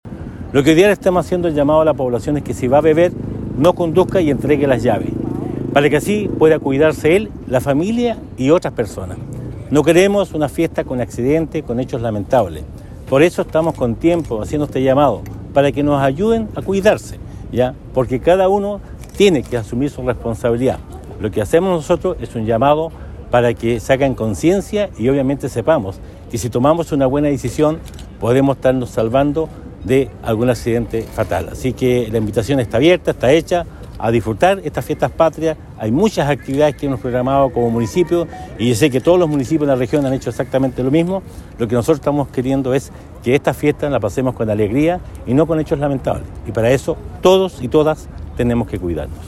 El jefe comunal osornino indicó que se hace un llamado extenso a la comunidad, para evitar el consumo de alcohol y conducir para evitar los accidentes vehiculares, pues de esta forma se podrá celebrar fiestas patrias de forma tranquila y segura.